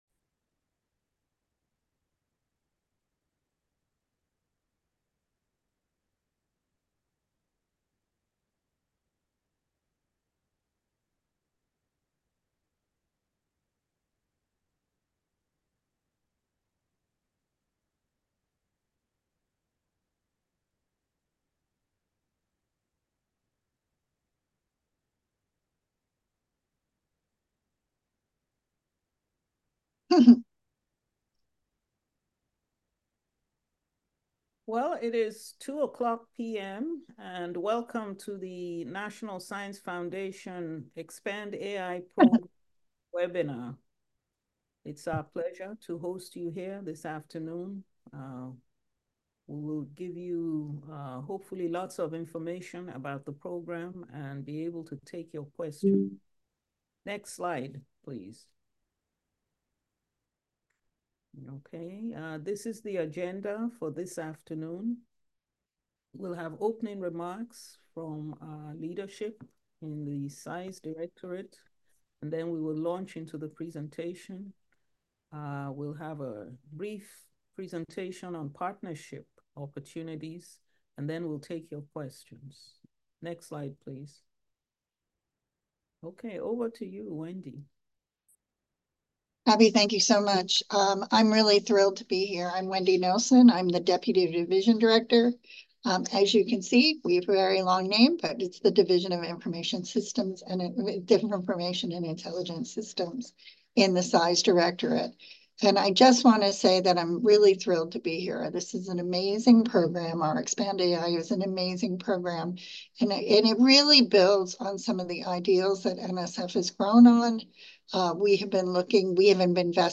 ExpandAI Program Webinar (October 2024)